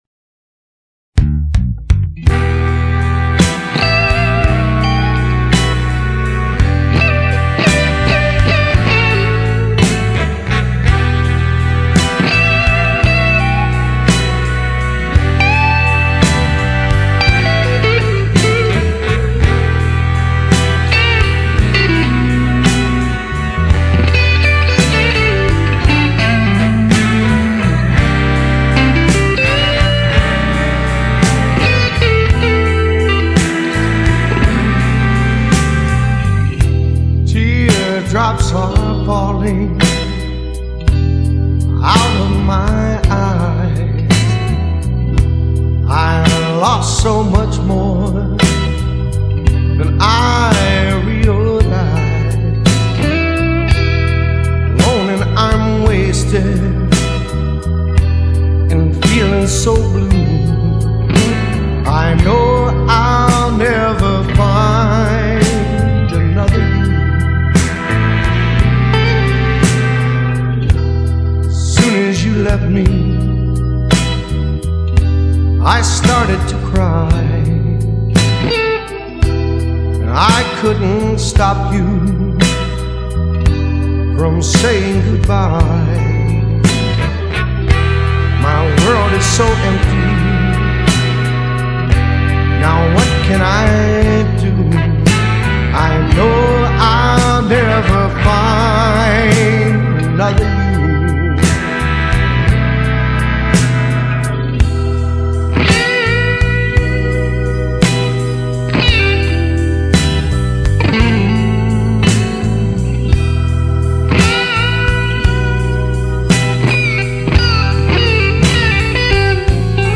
Pop/★Blues Paradise★